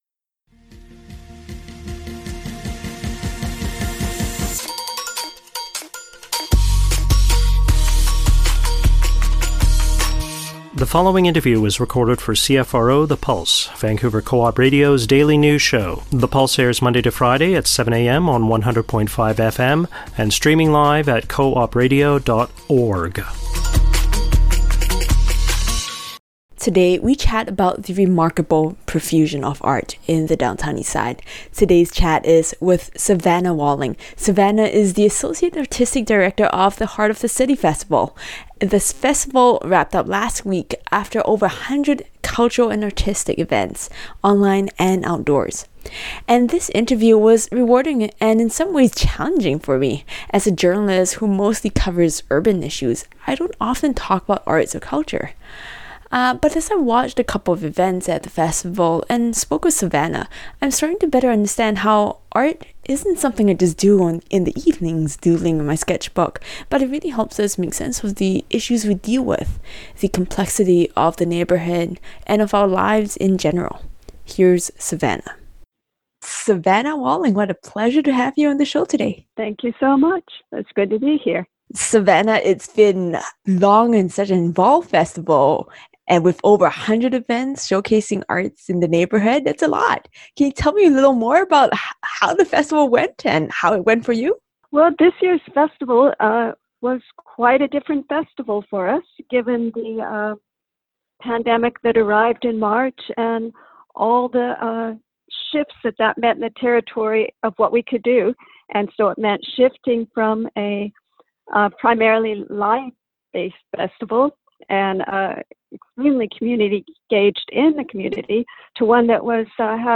the_pulse_interview_nov_12_podcast.mp3